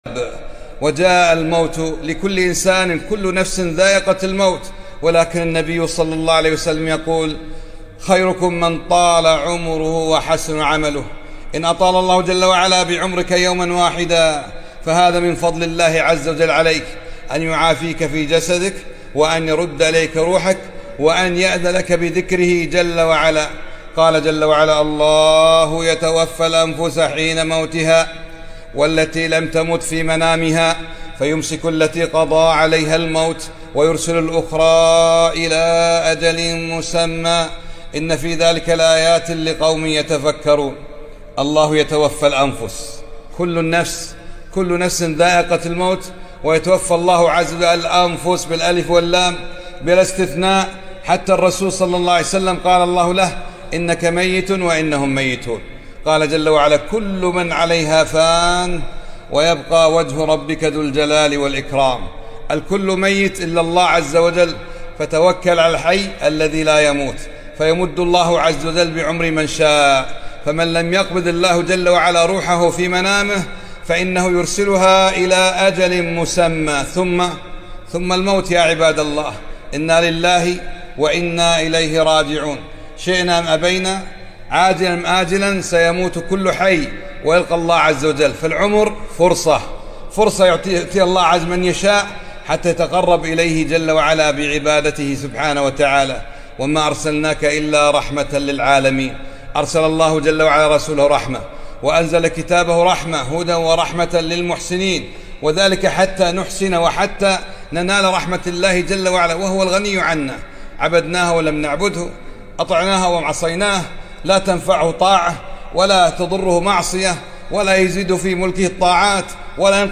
موعظة بليغة عن الموت والبعث بعد الموت